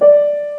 钢琴键 C3 C6 " A4
描述：Piano sounds individual keys as named
标签： 音高 钢琴 仪器
声道立体声